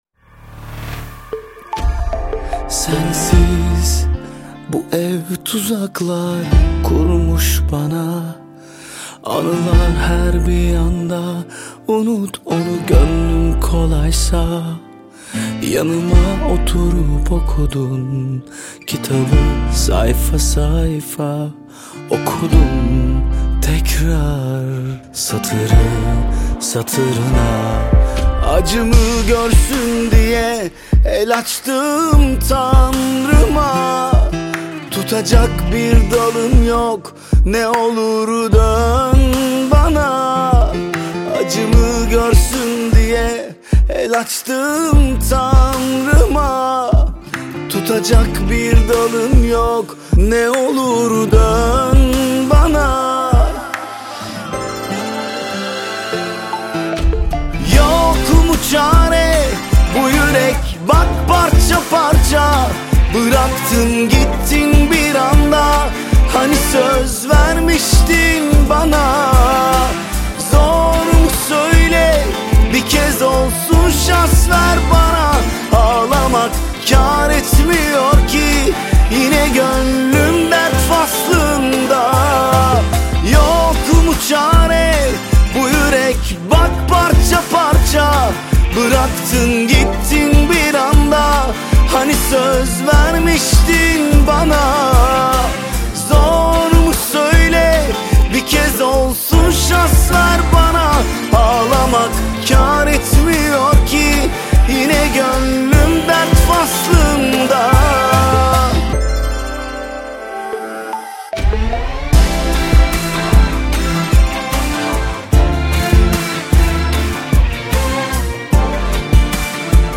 موزیک ترکی